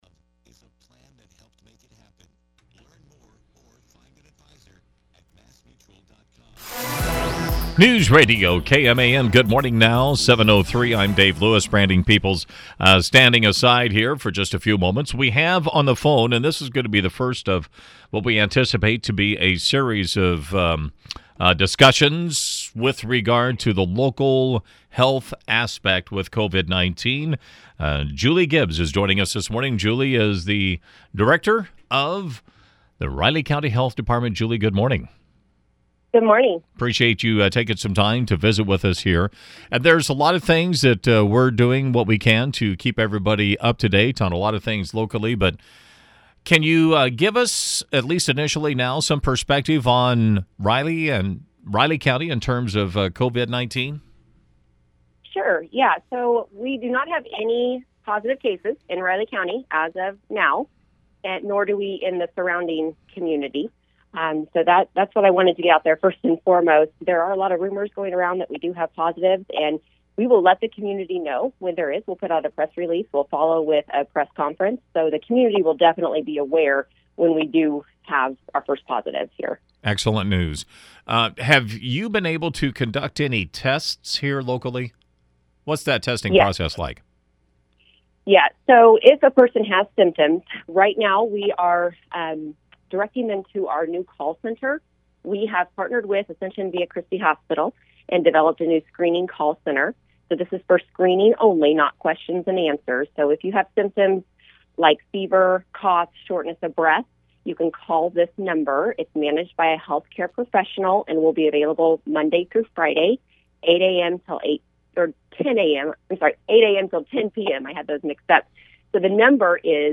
Riley County Health Department Director Julie Gibbs joined us for a daily update on local response to COVID-19 and some tips for the public to prevent the spread of illness.